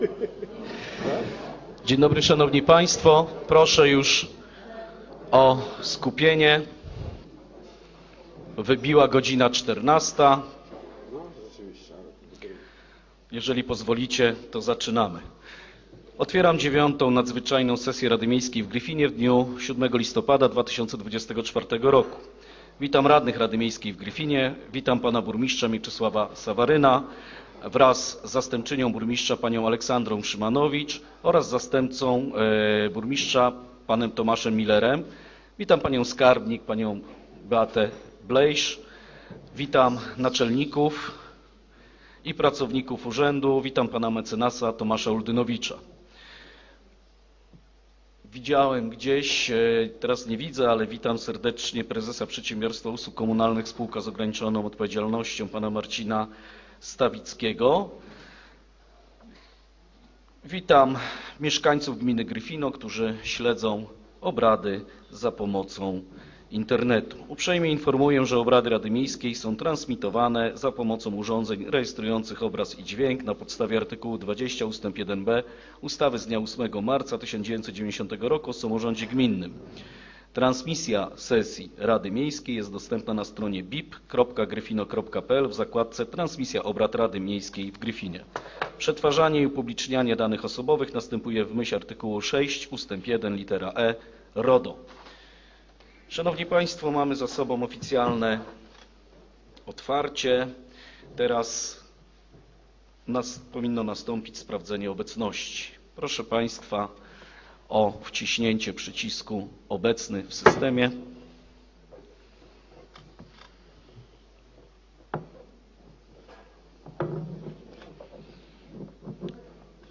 Zapis audio przebiegu 9 SESJI RADY MIEJSKIEJ